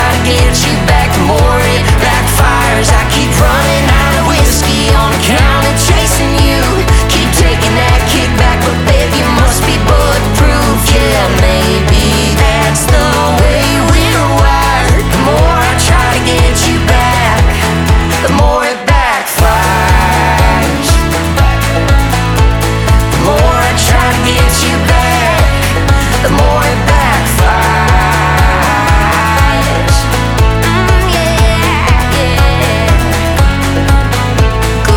Country Pop
Жанр: Поп музыка / Кантри